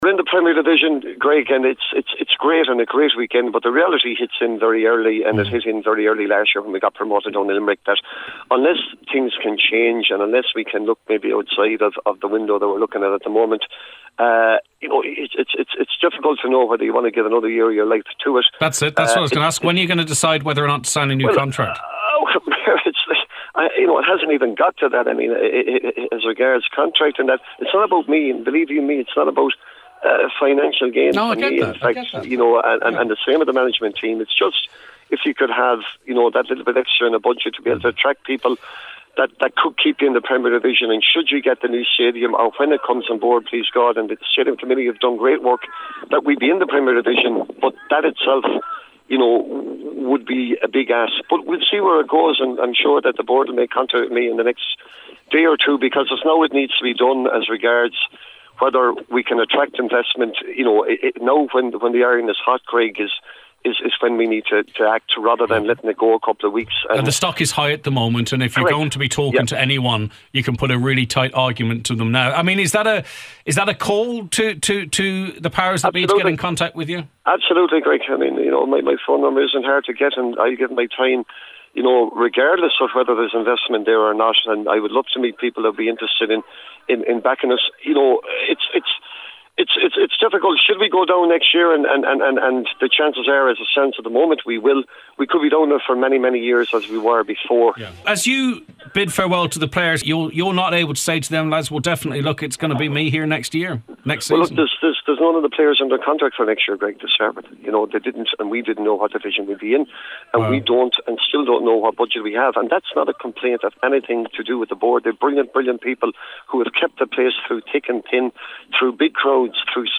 on the Nine till Noon Show this morning